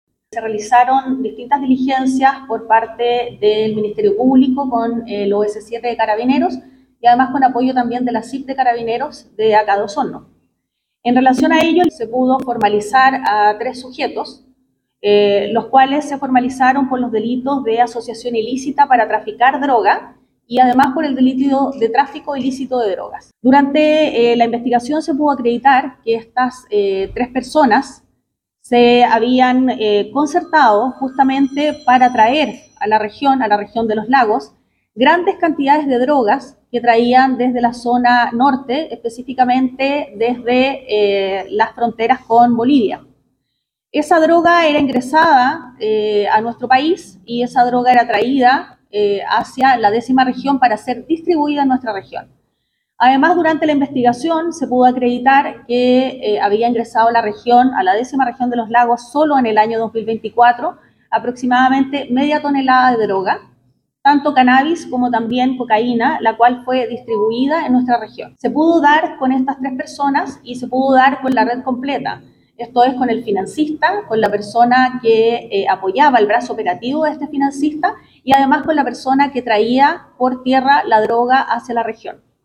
Detalles que entregó la fiscal jefe de Osorno, María Angélica de Miguel, quien reafirmó que fueron aprehendidos todos los integrantes de esta red de tráfico, desde el financista hasta quién transportaba estas sustancias ilícitas.
07-FISCAL-MARIA-ANGELICA-DE-MIGUEL.mp3